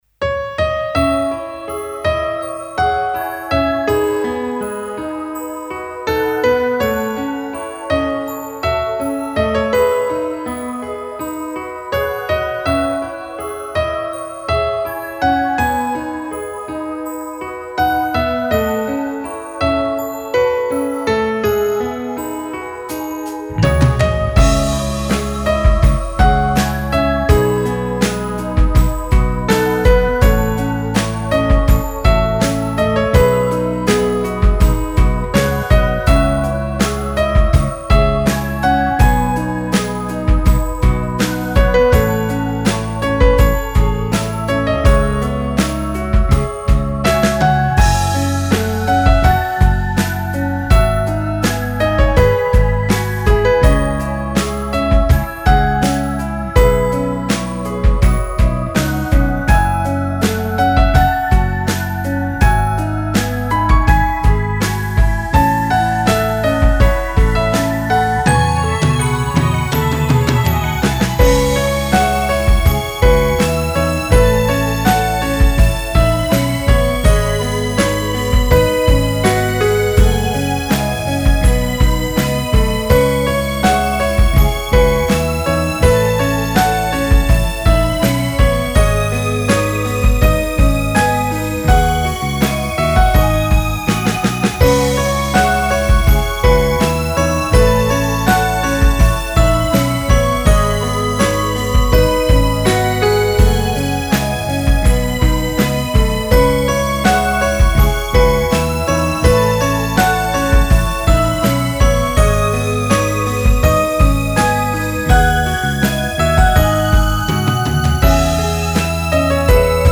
フリーBGM イベントシーン 切ない・悲しい